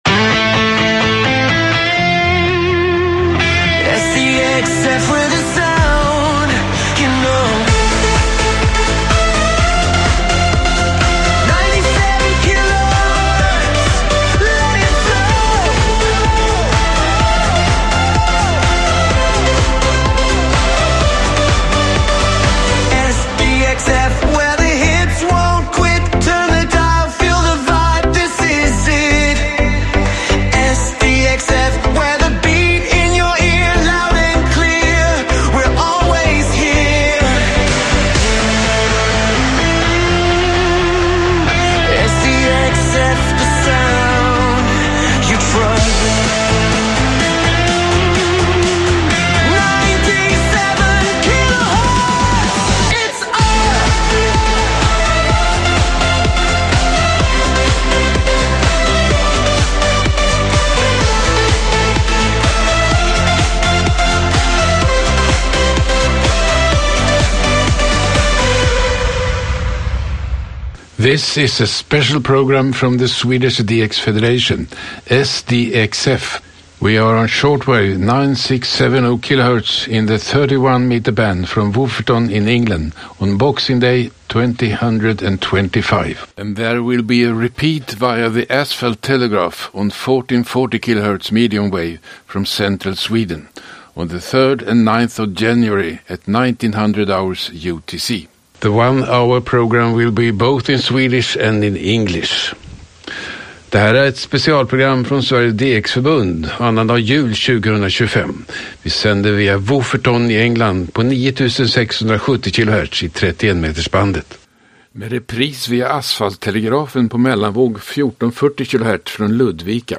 sändes på annandag jul från Wofferton samt via Asfaltstelegrafen i Ludvika
intervju